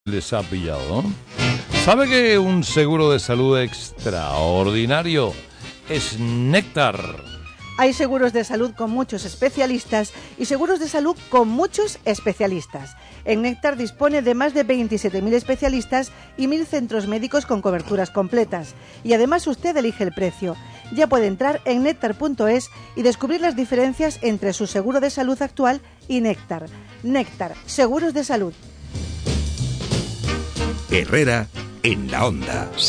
Para dar mayor notoriedad a la campaña, además de las cuñas grabadas, se realizaron menciones en directo con los conductores de los principales programas de la mañana:
Mención Carlos Herrera